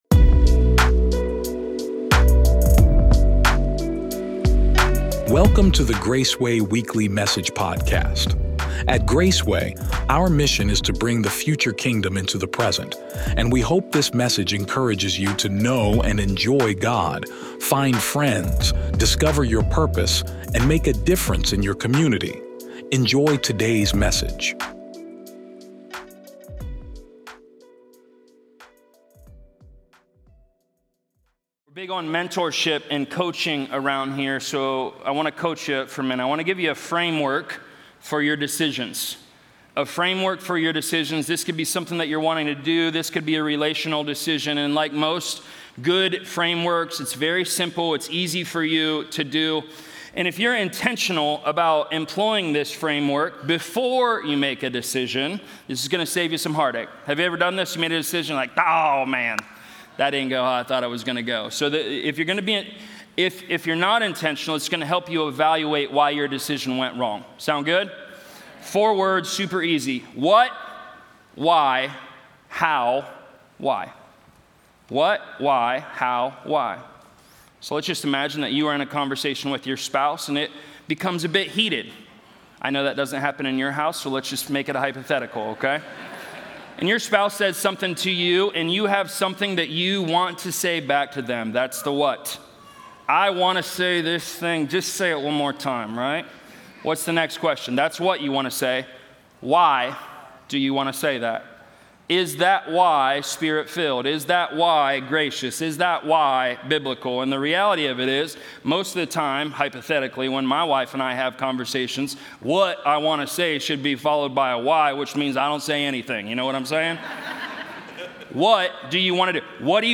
Every week on the Graceway podcast, you’ll hear practical, relevant, biblical perspectives on topics that matter to you